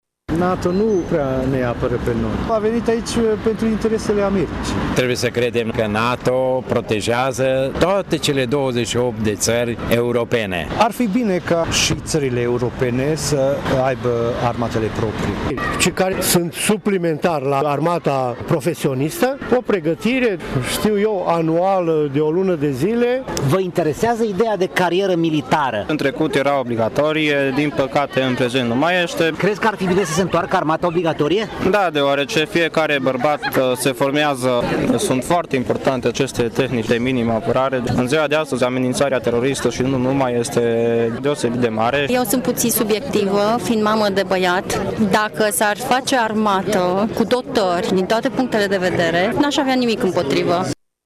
Cetățenii prezenți la manifestări au păreri împărțite în legătură revenirea la stagiul militar obligatoriu.